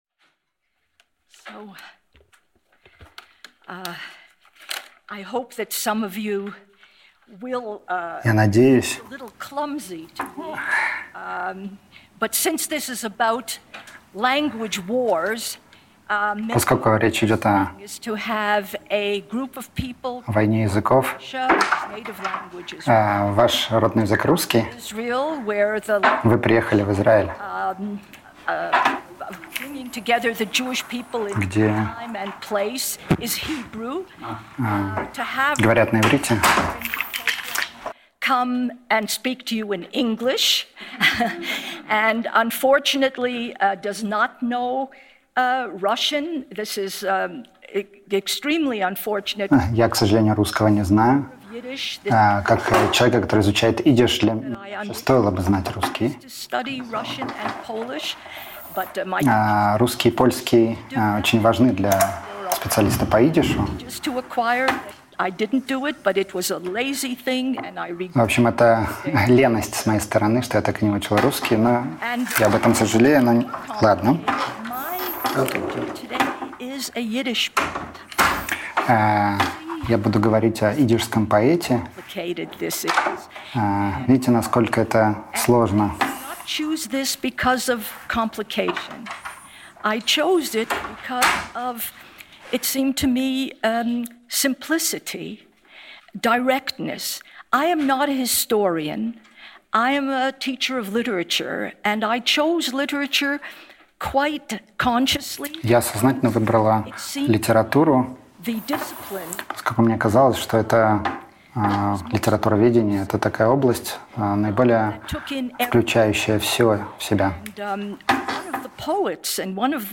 Аудиокнига Караванная революция | Библиотека аудиокниг